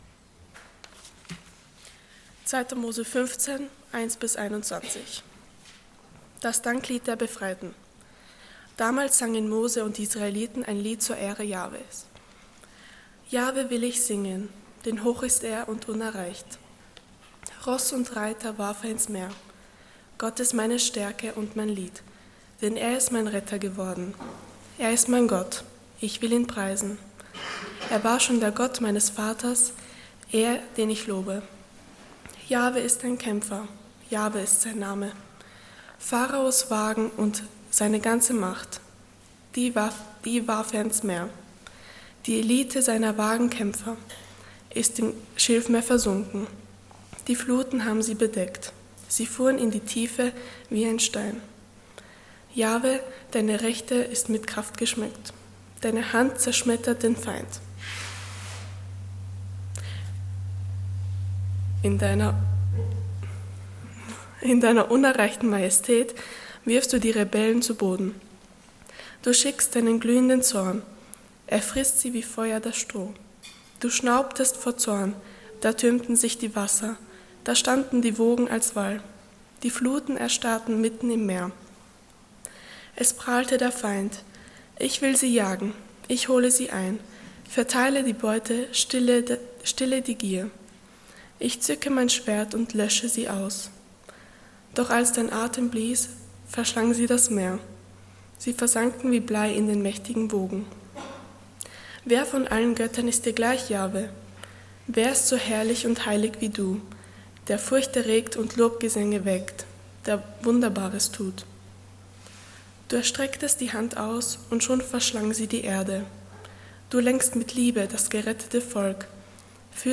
Passage: 2. Mose 15,1-21 Dienstart: Sonntag Morgen Ehre, wem Ehre gebührt Themen: Ehre , Lobpreis « Kein Aus-Weg, aber gerettet Warum Engel?